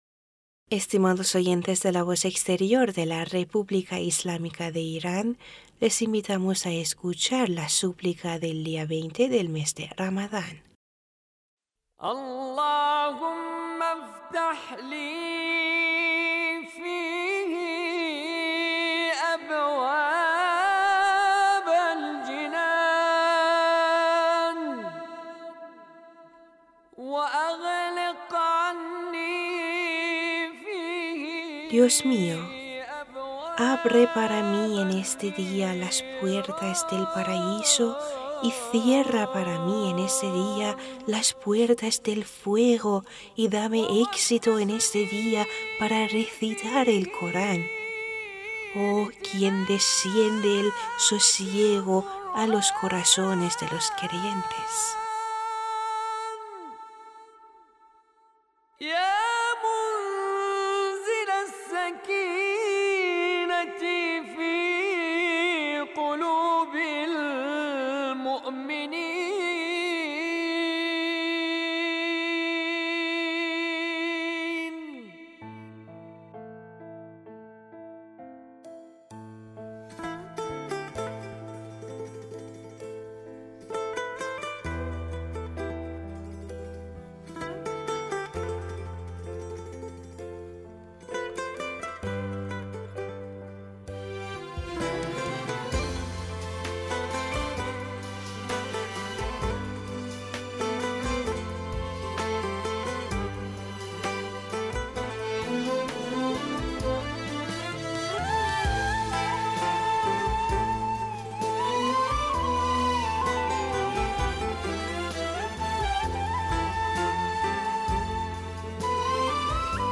Súplica de 20 día de mes de Ramadán